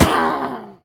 Minecraft Version Minecraft Version snapshot Latest Release | Latest Snapshot snapshot / assets / minecraft / sounds / entity / witch / death1.ogg Compare With Compare With Latest Release | Latest Snapshot